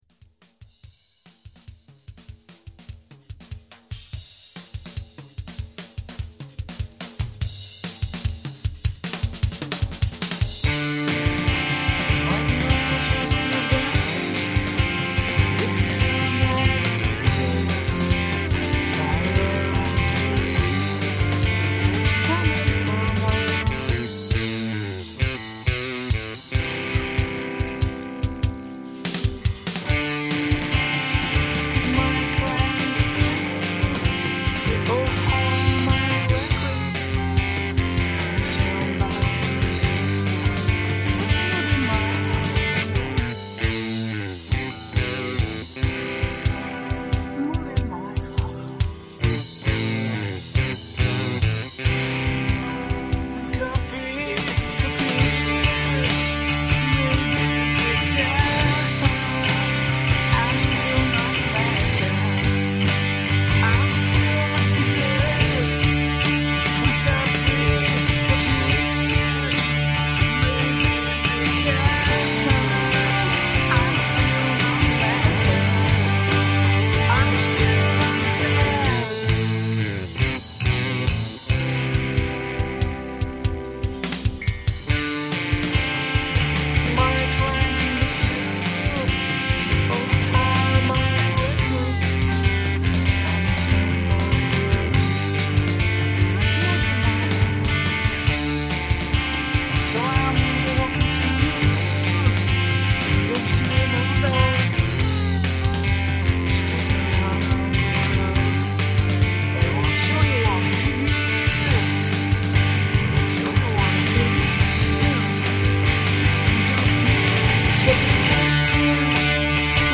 zpěv, kytara
bicí
basová kytara